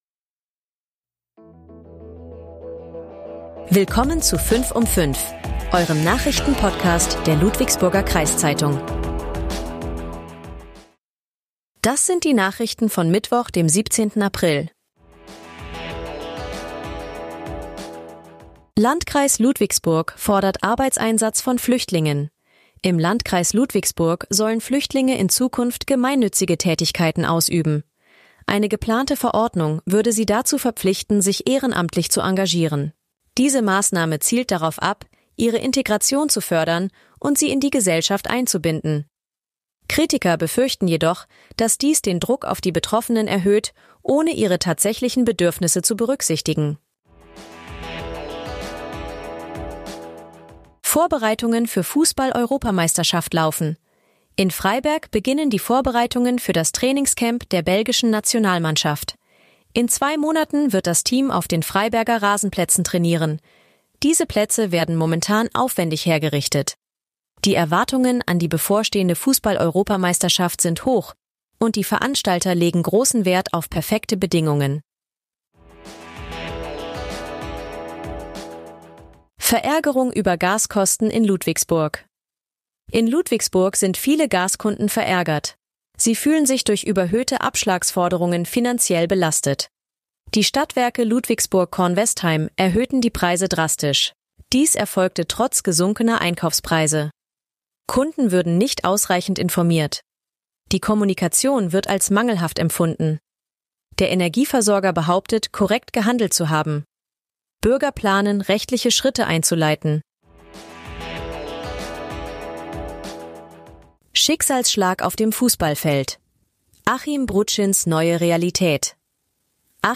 Euer Nachrichten-Podcast der Ludwigsburger Kreiszeitung